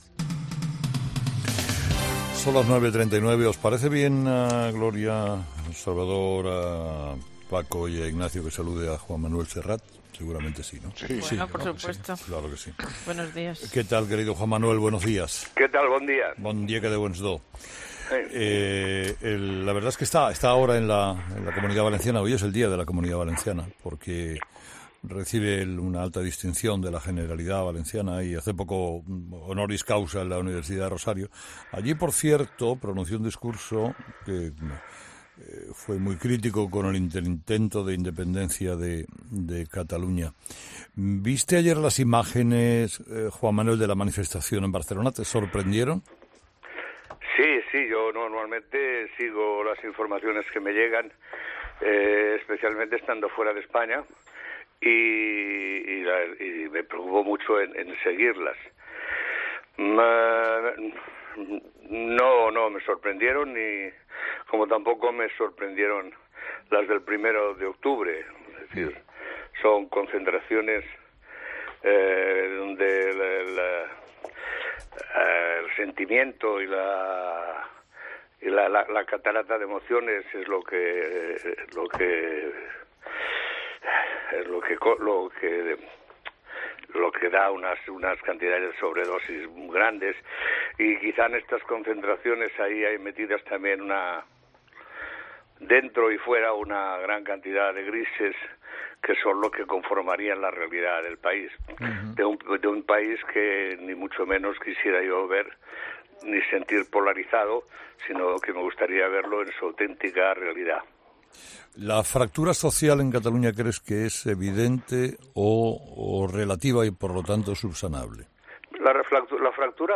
El cantante Juan Manuel Serrat ha declarado en 'Herrera en COPE'que no le sorprendió el éxito de la manifestación de este domingo en Barcelona como tampoco las del 1-O porque son "actos cargados de emociones" pero entre los participantes, añade, seguro que hay “gran cantidad de grises que son la realidad de un país que ni mucho menos quiero ver polarizado”.